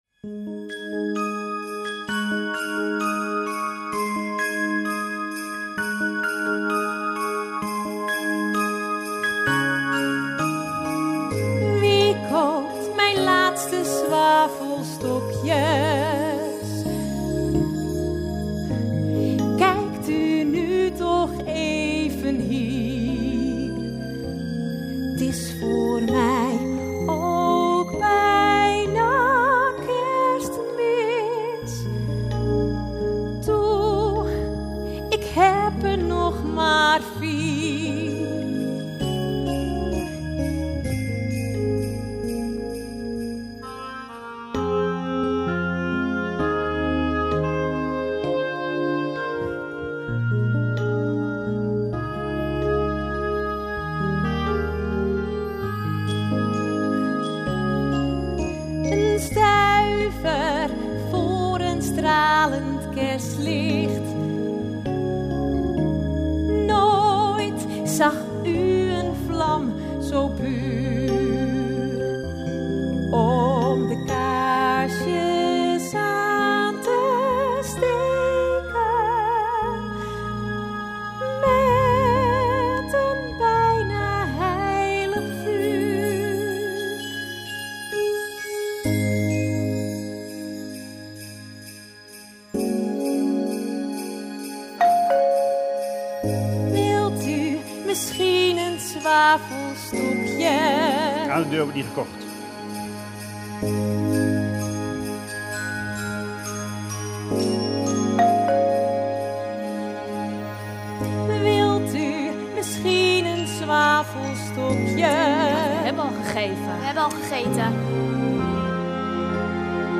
Live versie